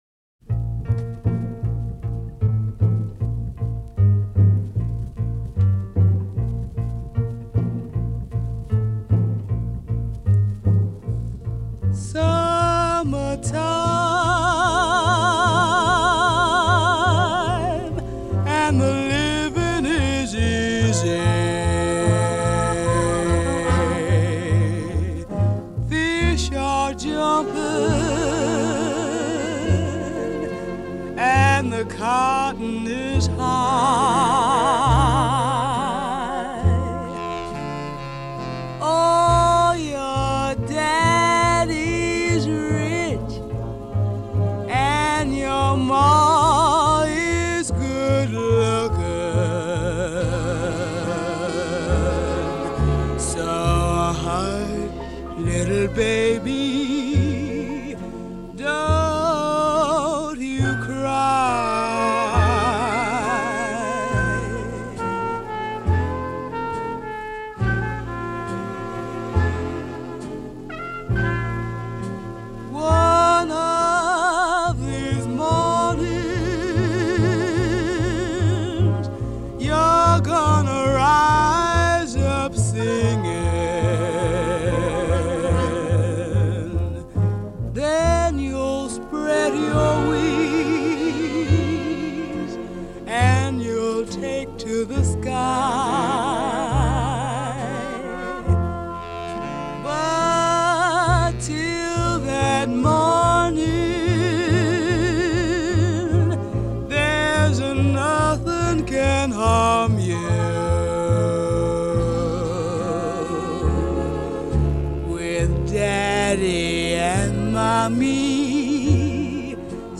também na voz light